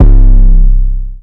808 6 [ pyrex 2 ].wav